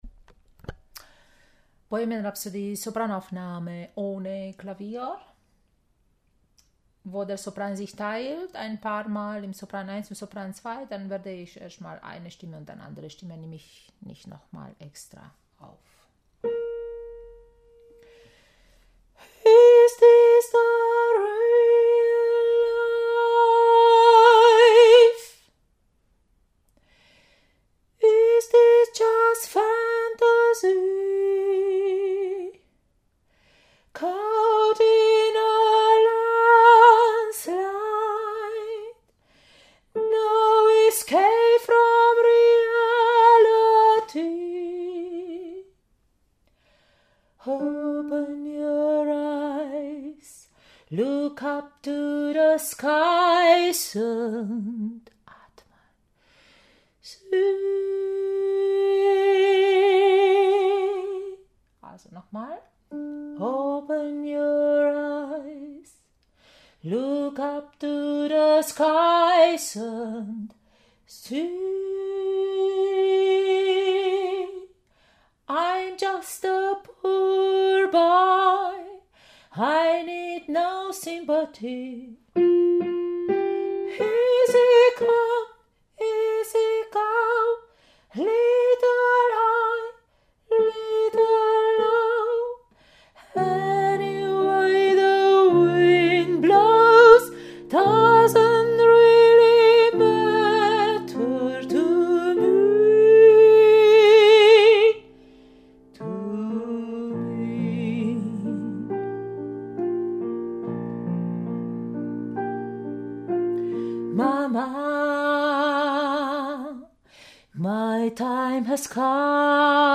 Bohemian Rhapsody – Sopran ohne Klavier